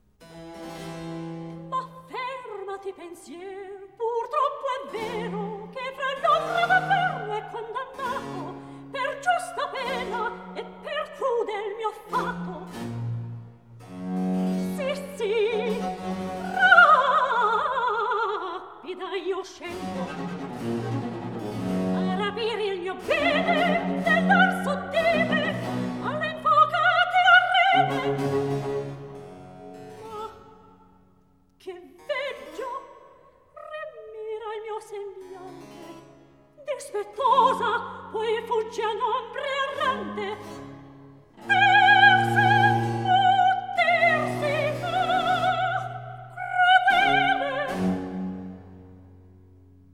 Recitativo